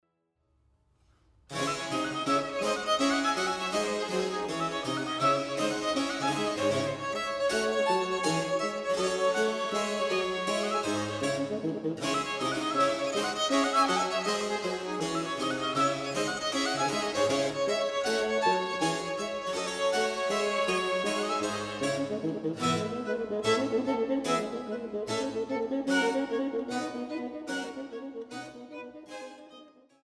für zwei Violinen, Fagott und B. c.
Largo